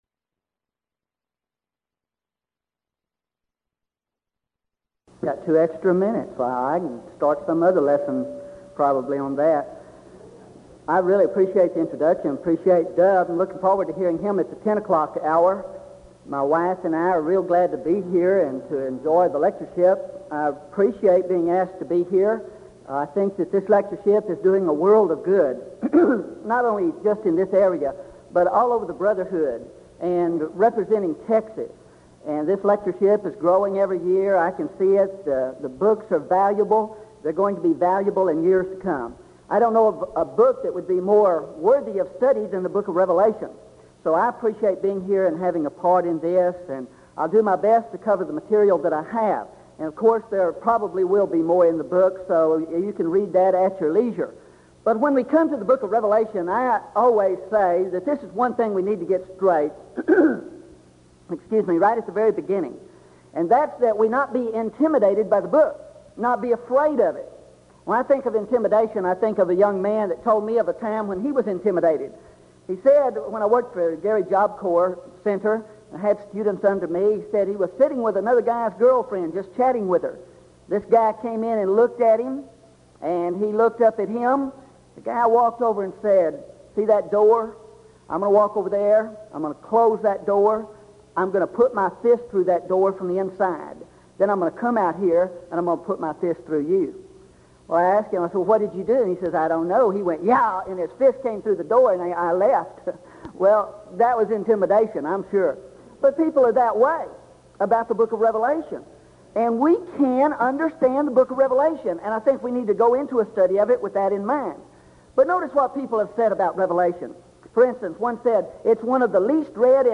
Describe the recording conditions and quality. Event: 1984 Denton Lectures Theme/Title: Studies in the Book of Revelation